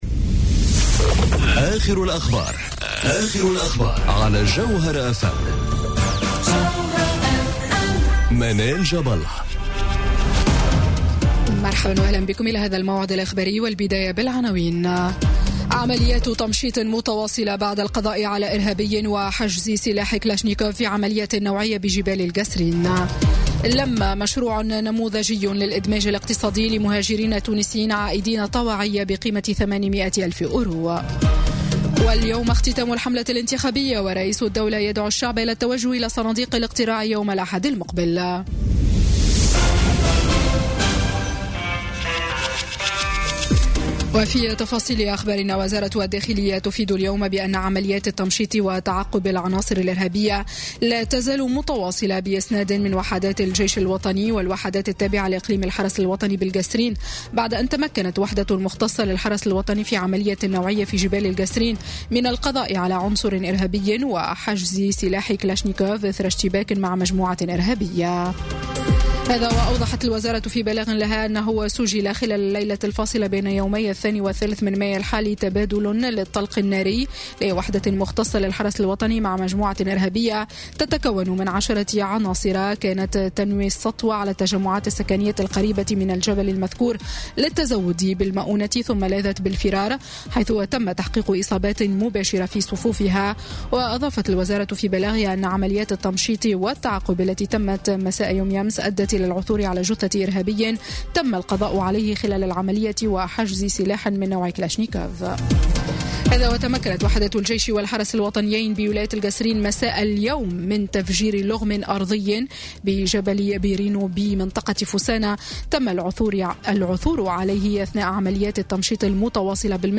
نشرة أخبار السابعة مساءً ليوم الجمعة 4 ماي 2018